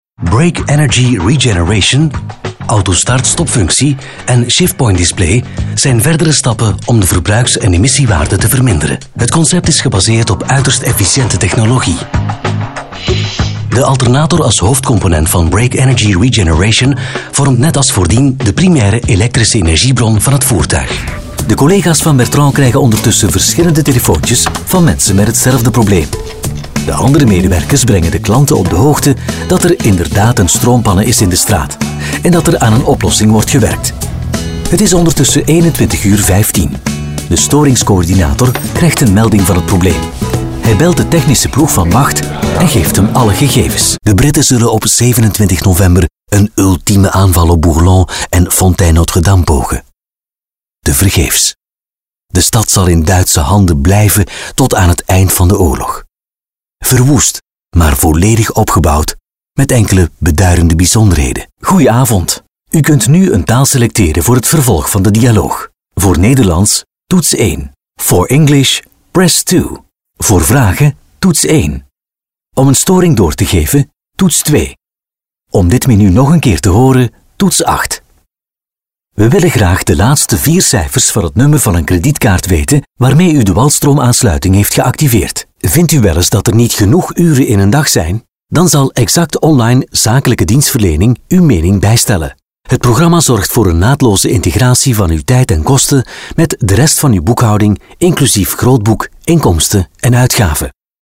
Allround Flemish voice over, wide range of styles
Sprechprobe: Industrie (Muttersprache):